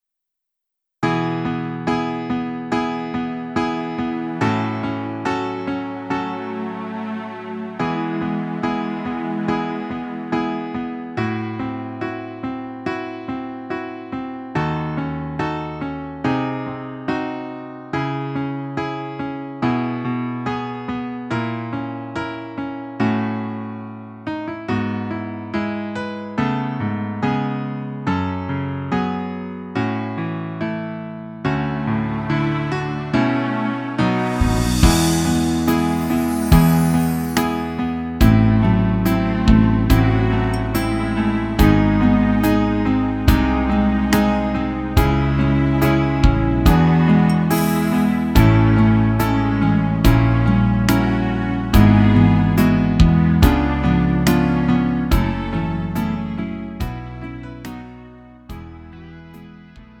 음정 원키 4:07
장르 가요 구분 Lite MR